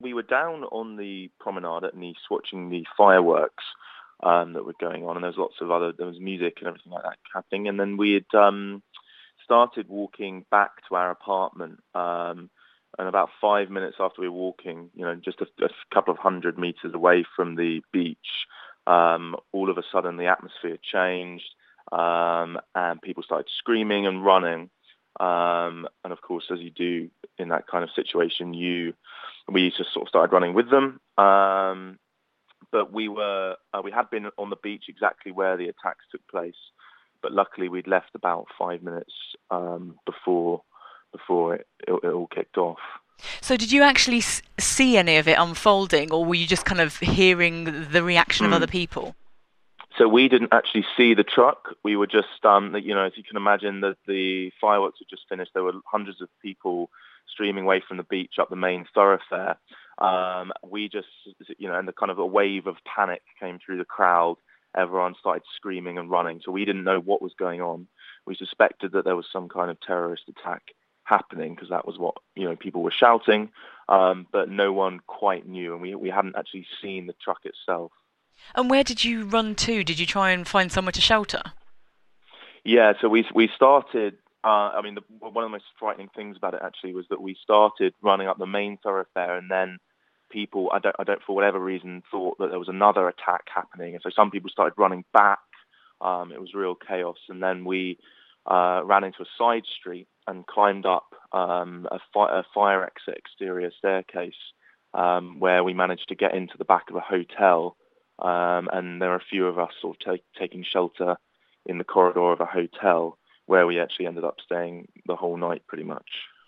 A Dorset man has been describing the horror of being caught up in the terrorist attack in Nice.